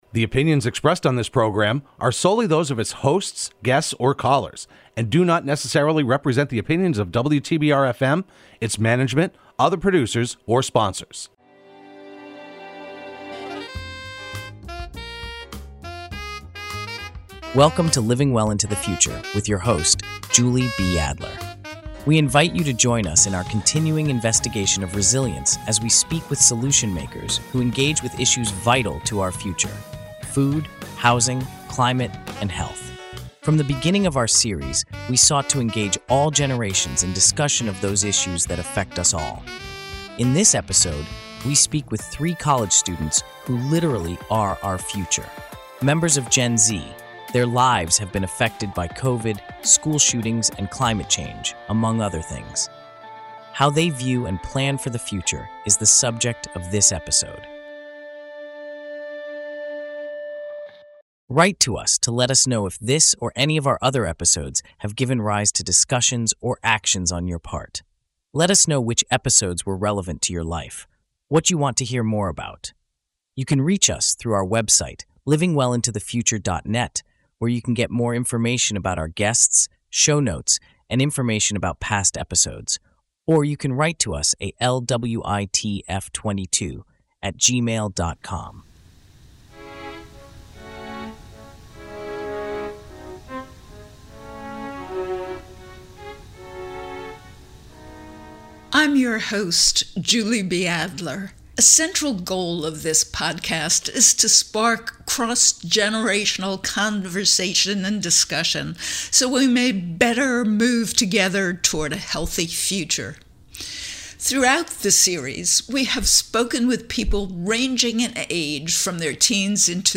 LWITF delves into the perspectives of three Gen Z college students on how global and local crises have shaped their outlook. The students discuss the impact of COVID-19, climate change, school shootings, and social media on their generation.